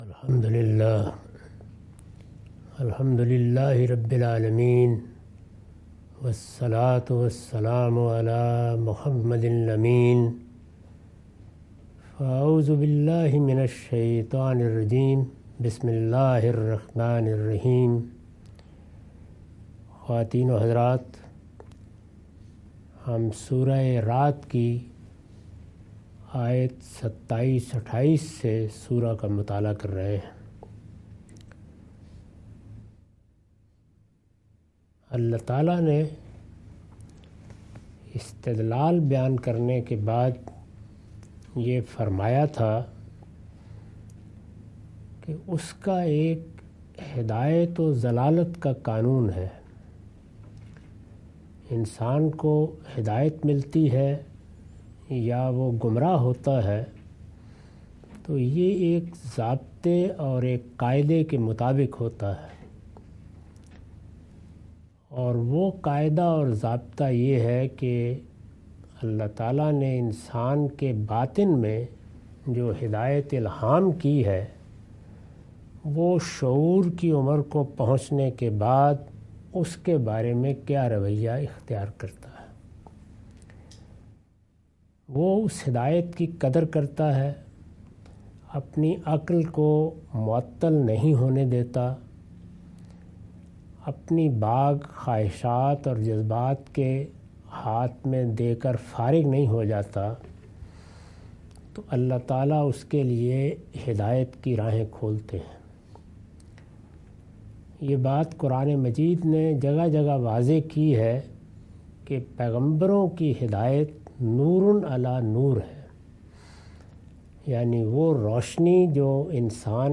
Surah Ar-Rad - A lecture of Tafseer-ul-Quran – Al-Bayan by Javed Ahmad Ghamidi. Commentary and explanation of verses 28-29.